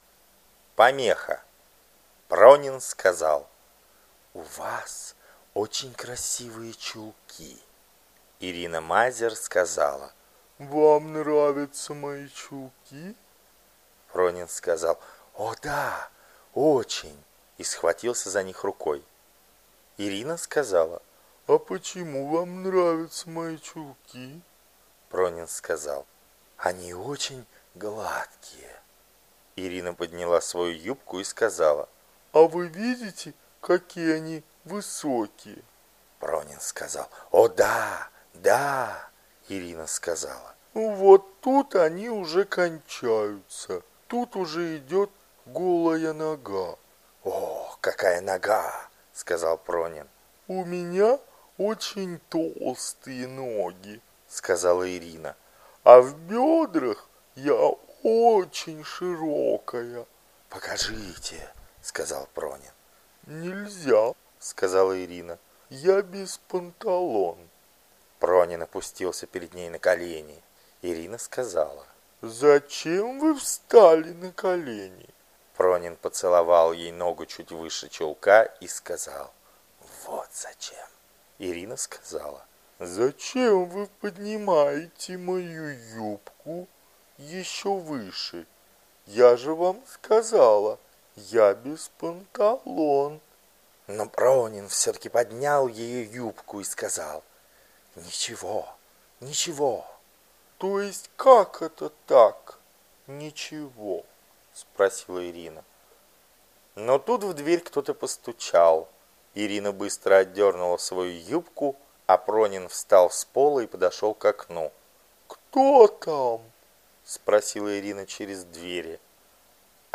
Галерея Хармс 5 часов АУДИОКНИГА